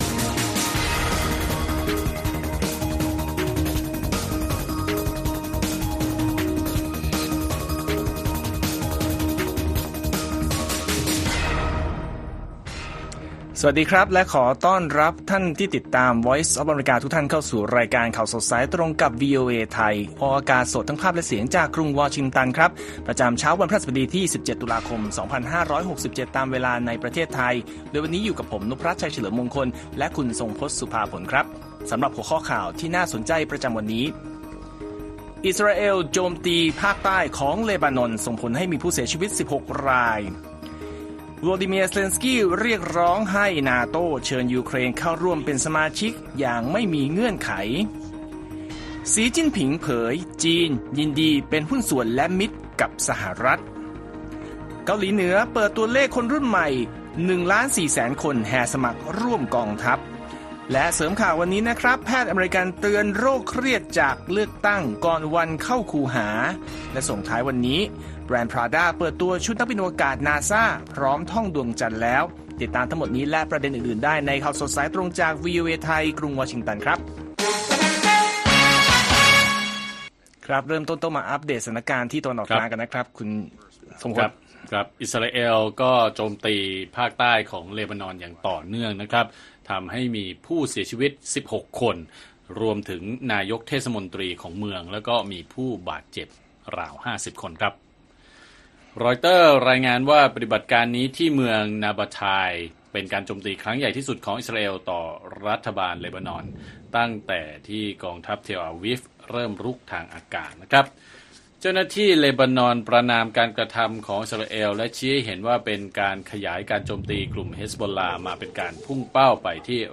ข่าวสดสายตรงจากวีโอเอ ไทย ประจำวันพฤหัสบดีที่ 17 ตุลาคม 2567